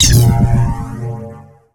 alien_punch_01.ogg